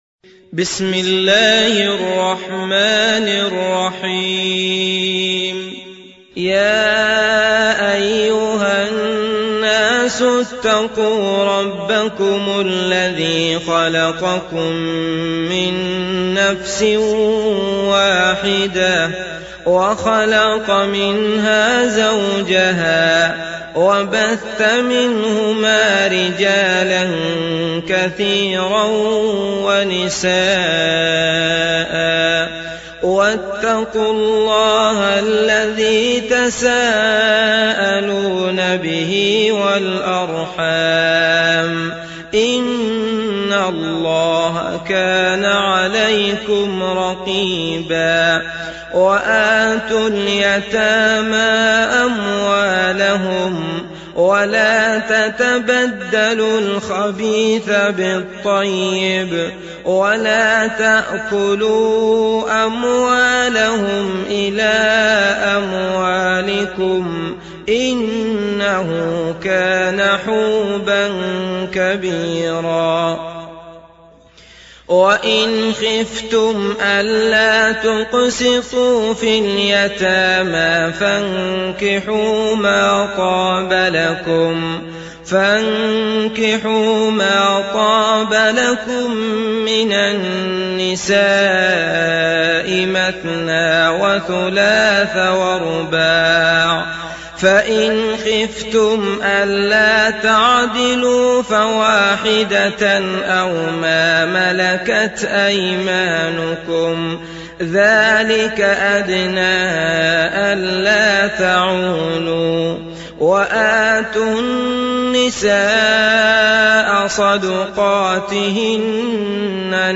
تحميل سورة النساء mp3 بصوت عبد الله المطرود برواية حفص عن عاصم, تحميل استماع القرآن الكريم على الجوال mp3 كاملا بروابط مباشرة وسريعة